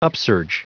Prononciation du mot upsurge en anglais (fichier audio)
Prononciation du mot : upsurge